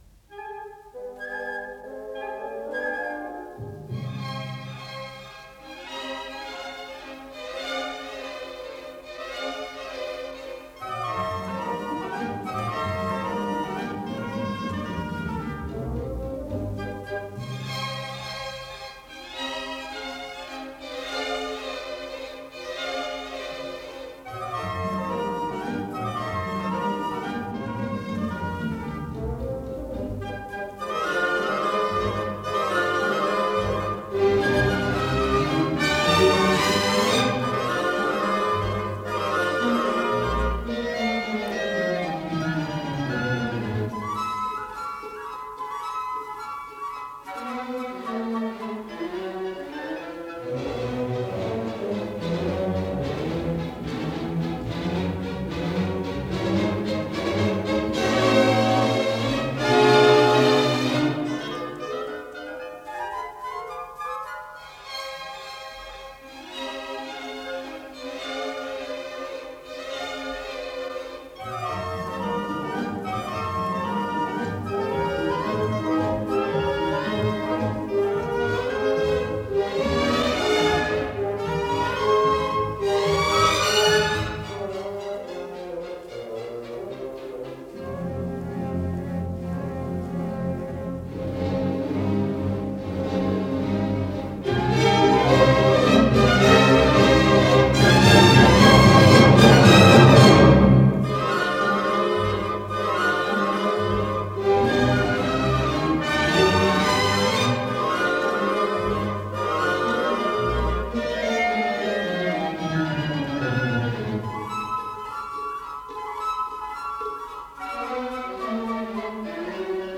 Соль минор.
ИсполнителиГосударственный симфонический оркестр СССР
Художественный руководитель и дирижёр - Евгений Светланов
ВариантДубль моно